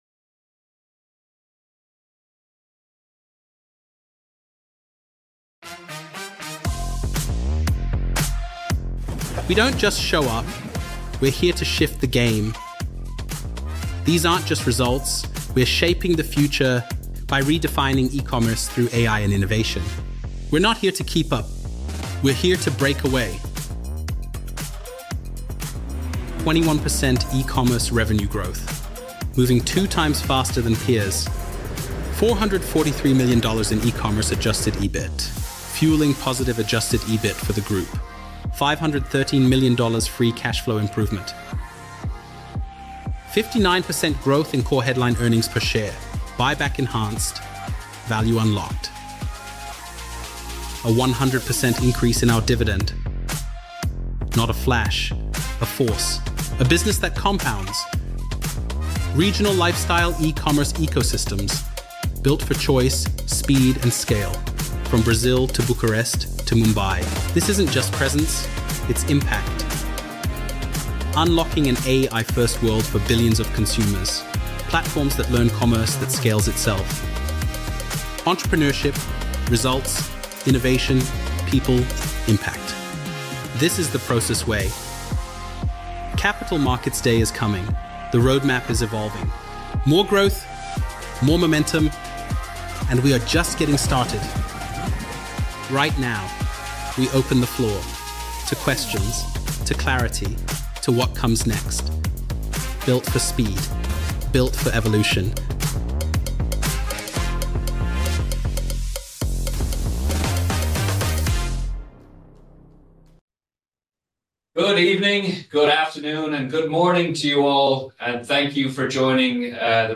fy25-results-webcast-recording.mp3